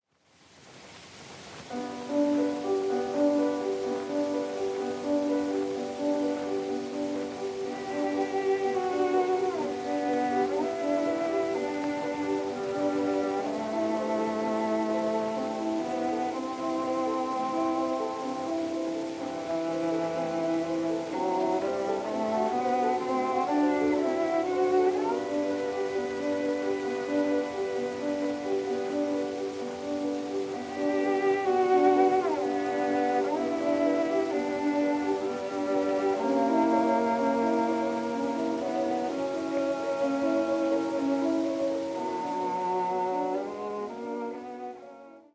古い録音で聴くチェロの響きの奥深さよ。
録音：1916〜20年　モノラル録音